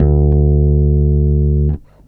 16-D2.wav